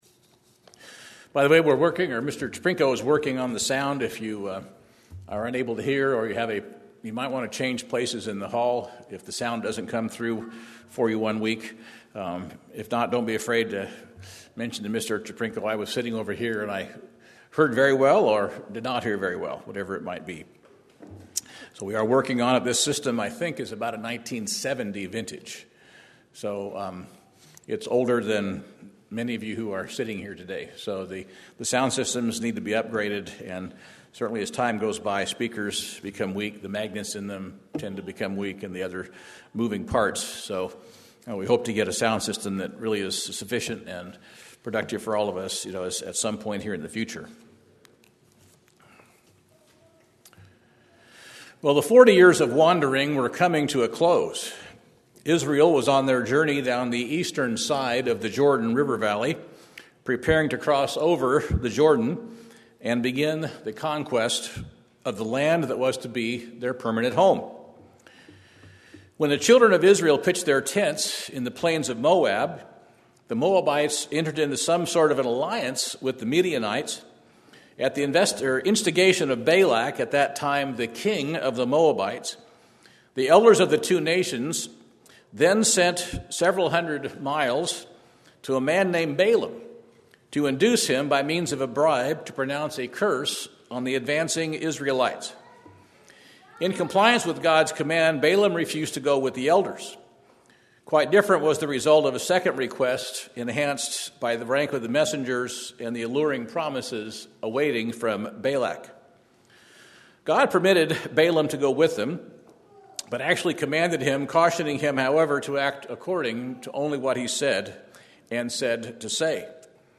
Balaam was faithful up to a point then he sold out Israel for personal gain. How is that different from conditions today? Listen to this informative sermon.